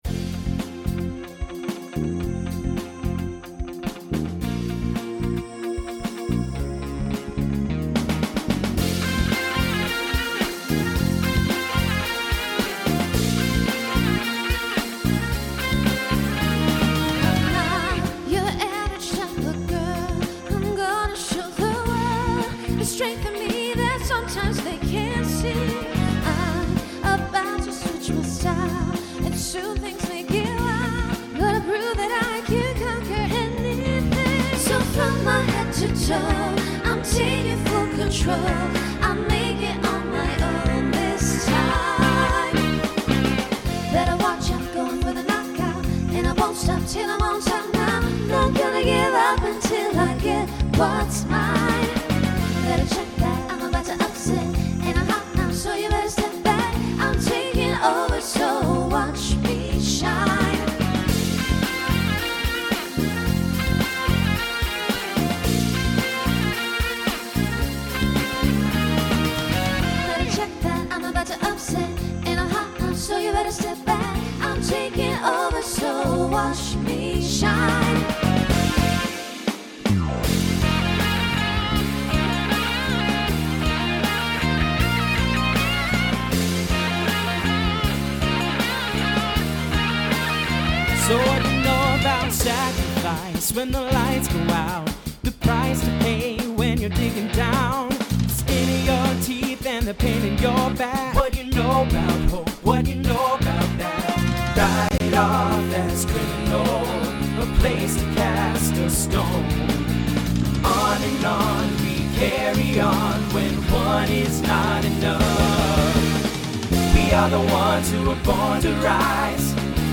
SSA/TTB
Instrumental combo
Pop/Dance , Rock